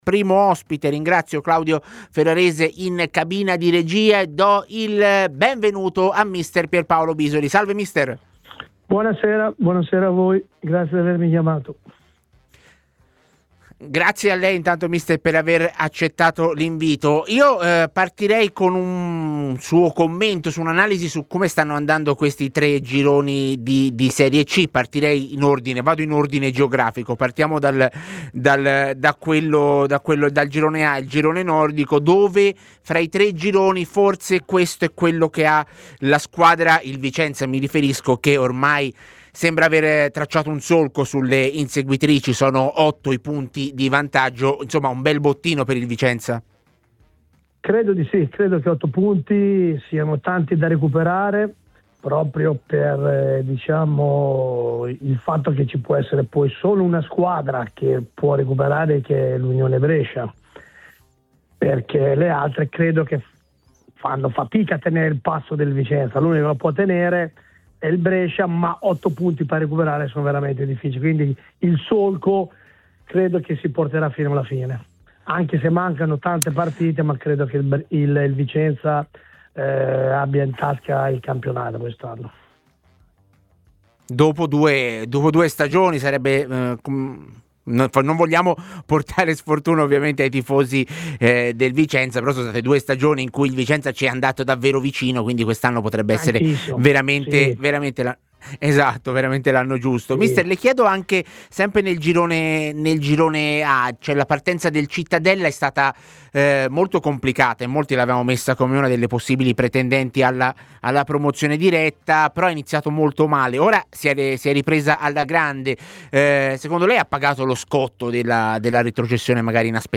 L'esperto tecnico Pierpaolo Bisoli , ai microfoni di TMW Radio nel corso della trasmissione 'A Tutta C', ha analizzato le ultime novità in Lega Pro.